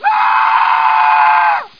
manscream1.mp3